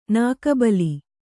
♪ nāka bali